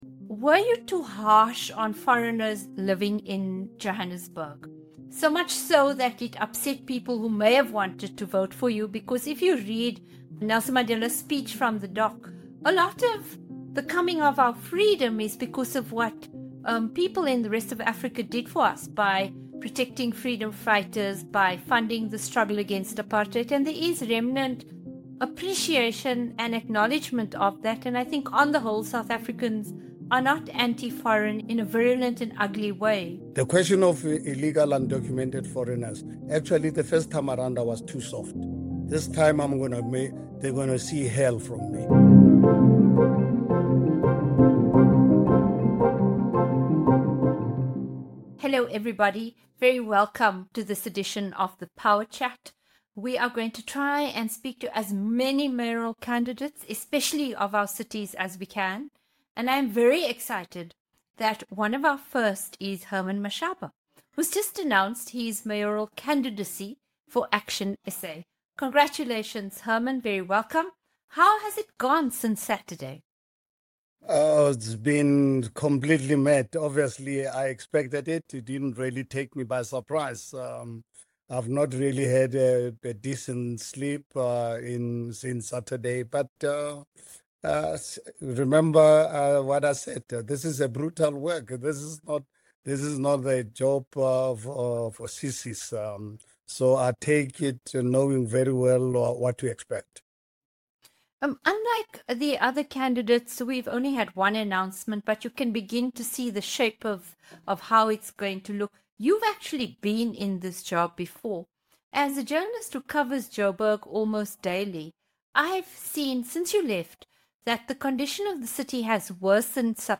In a wide-ranging interview, Daily Maverick’s Ferial Haffajee sits down with Herman Mashaba, president of ActionSA and mayoral candidate for Johannesburg, to probe his plans for a city in crisis. From the deepening water shortages and collapsing infrastructure to his hardline stance on undocumented immigrants, Mashaba lays out an agenda rooted in enforcement and administrative overhaul.